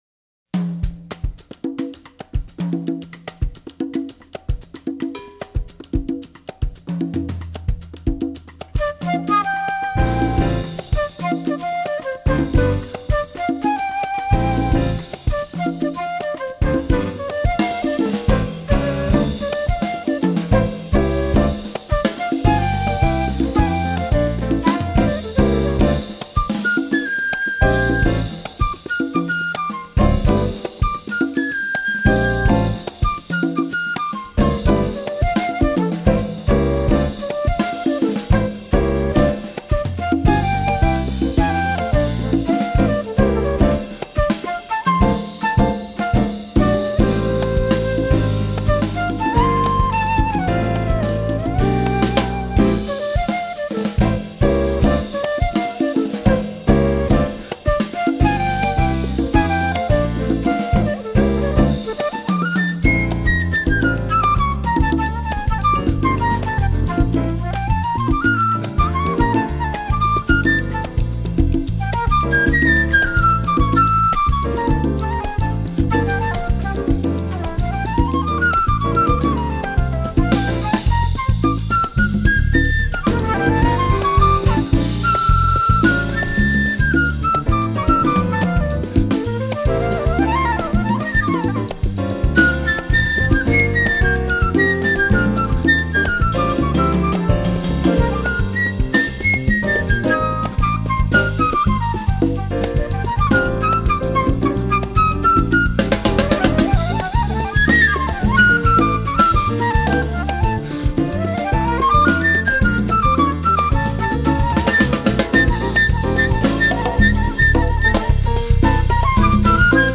vibes, flute, alto sax, marimba
Afro-Caribbean (and Afro-Brazilian) rhythms
FILE: Jazz